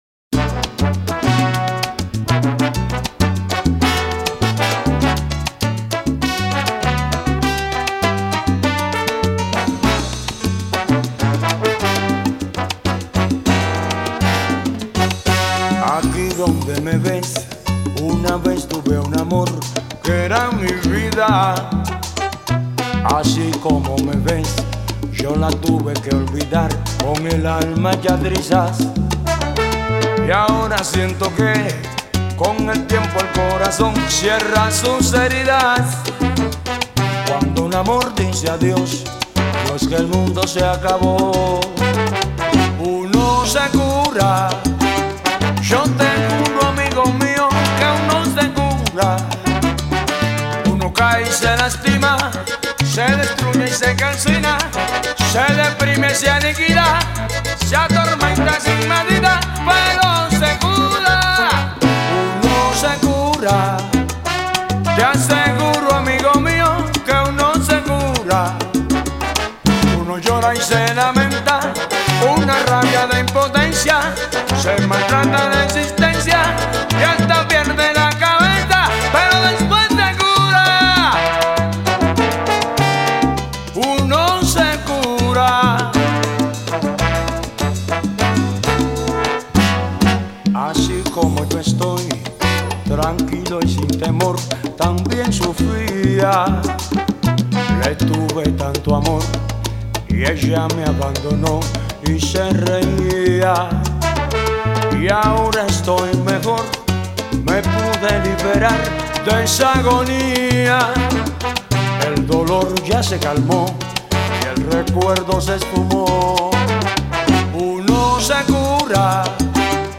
доминиканский певец